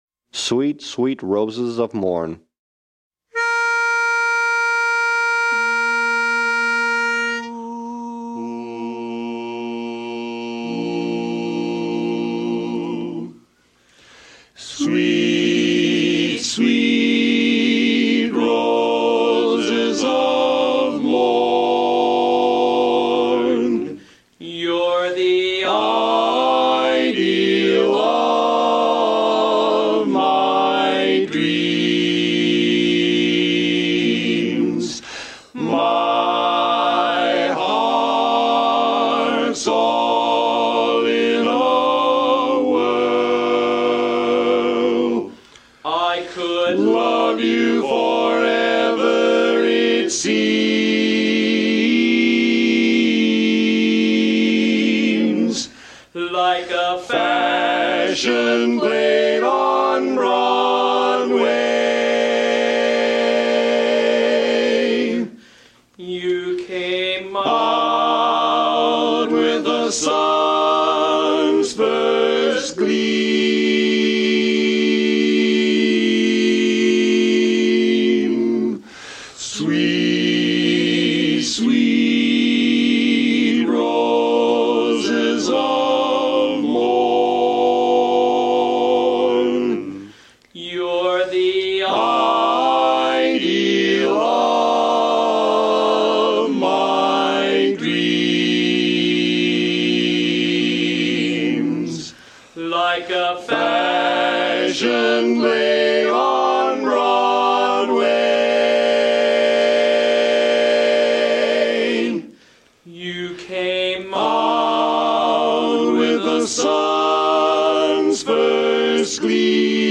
Von 19:15 bis 20:00 Uhr proben die NoNames, unser kleines, aber feines, Männerensemble, am gleichen Ort.
Sweet sweet Roses of Morn Bariton